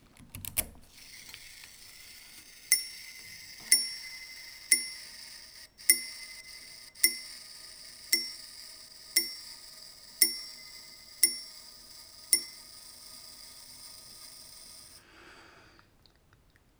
La montre est pourvue d’une sonnerie "à la demande"
En pressant sur la petite couronne au sommet du pendant, on déclenche la sonnerie dont le nombre de coups frappés sur le timbre correspond à l'heure indiquée sur le cadran. Si le mouvement a été modernisé, le timbre est d'origine, car il est parfaitement adapté à la boîte.